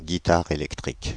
Ääntäminen
Ääntäminen Paris: IPA: [gi.ta.ʁ‿e.lɛk.tʁik] Haettu sana löytyi näillä lähdekielillä: ranska Käännös Konteksti Substantiivit 1. electric guitar soitin, musiikki Suku: f .